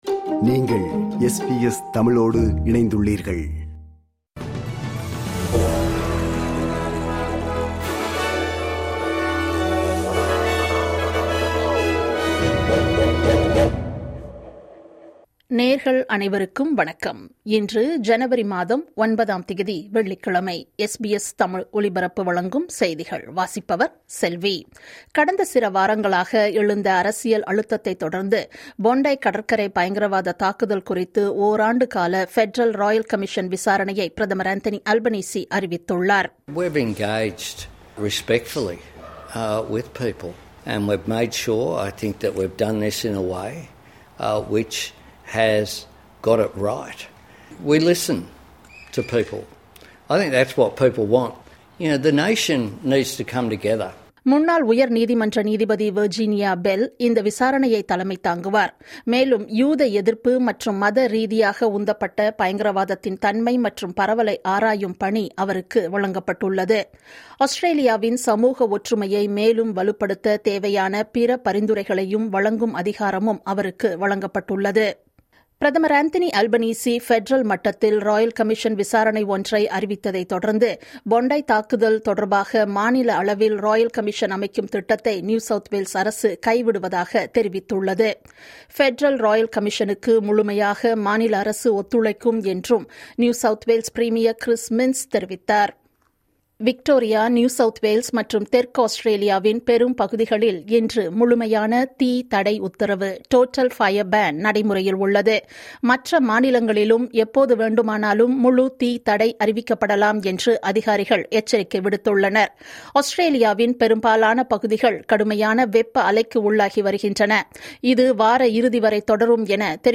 SBS தமிழ் ஒலிபரப்பின் இன்றைய (வெள்ளிக்கிழமை 09/01/2026) செய்திகள்.